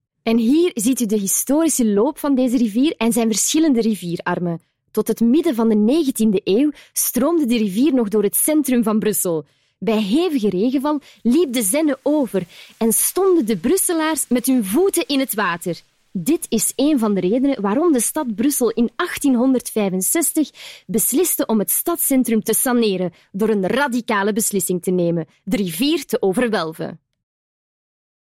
Commercial, Young, Natural, Versatile, Friendly
Audio guide